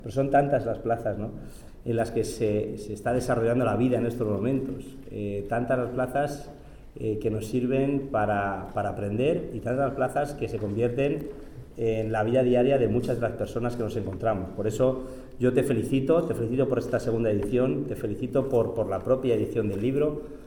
La Sala Capitular acoge la presentación del libro